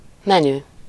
Ääntäminen
Synonyymit carte Ääntäminen France: IPA: [mə.ny] Haettu sana löytyi näillä lähdekielillä: ranska Käännös Ääninäyte Substantiivit 1. étlap Muut/tuntemattomat 2. menü Suku: m .